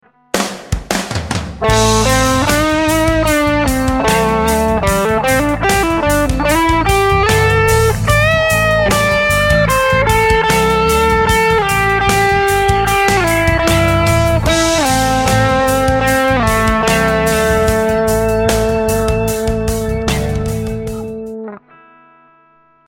Vibrato is the wobbly “vibrating” sound that is applied to longer notes.
Sexy Solo Vibrato
Listen to the solo with all these elements applied to hear much more expressive it sounds.